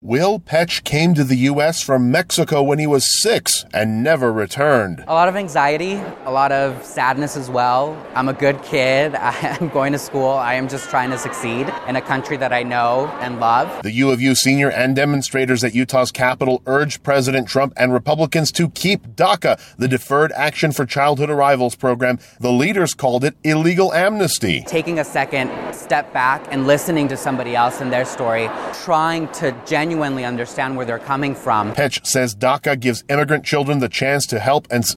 Demonstrators at the Utah capitol tell President Trump to keep the Deferred Action for Childhood Arrivals program, fearing deportation without it.